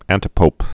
(ăntĭ-pōp)